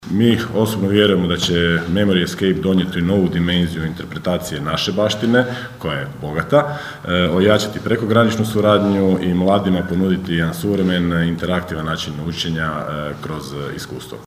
Zamjenik gradonačelnika Goran Vlačić je uvjeren: (